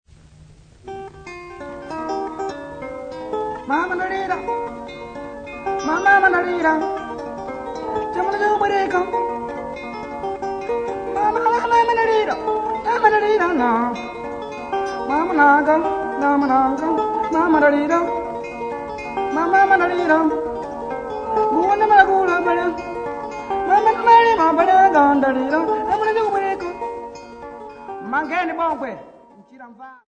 Popular music--Africa
Field recordings
sound recording-musical
A song accompanied by string instrument